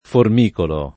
formicolare